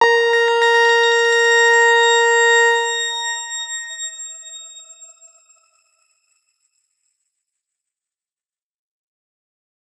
X_Grain-A#4-ff.wav